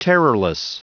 Prononciation du mot terrorless en anglais (fichier audio)
Prononciation du mot : terrorless